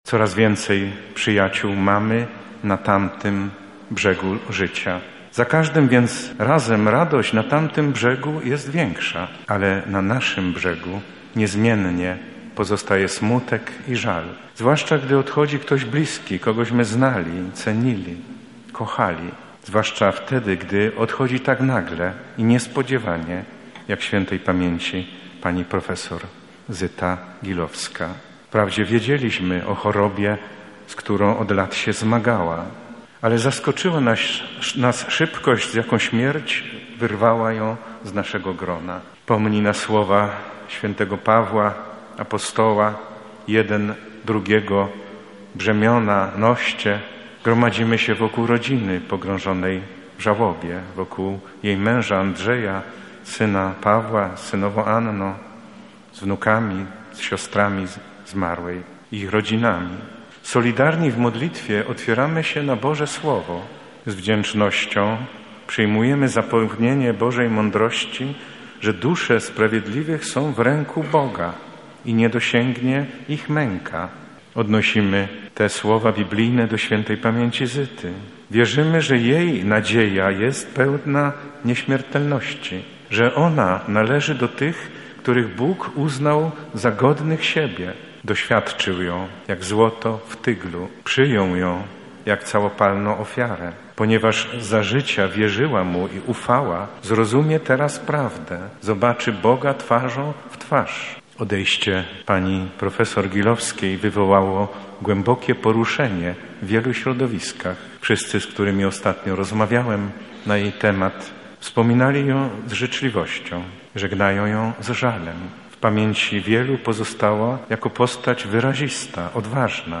Pogrzeb Gilowskiej 1
– mówił w trakcie homilii arcybiskup Stanisław Budzik